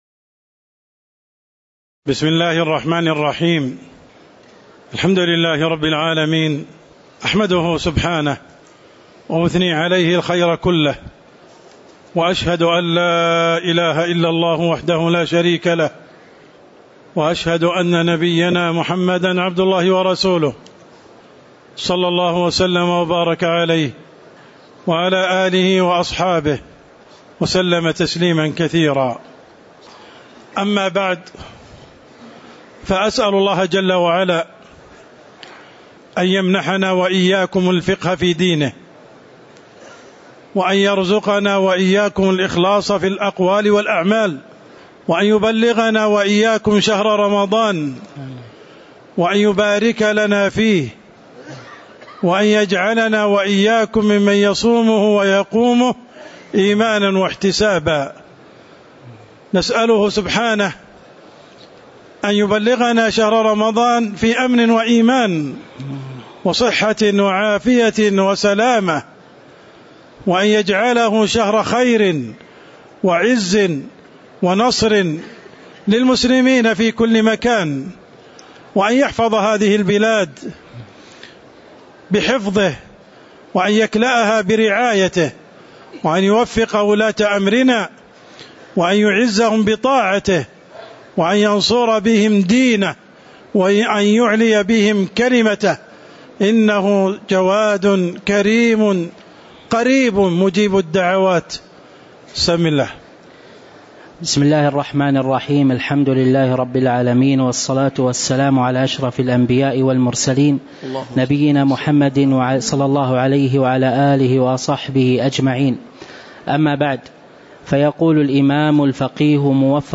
تاريخ النشر ٢٥ شعبان ١٤٤٠ هـ المكان: المسجد النبوي الشيخ